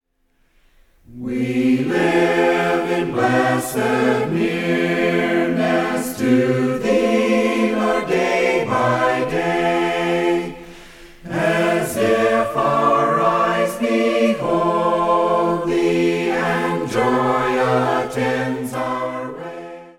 A cappella mixed group sings many beautiful hymns.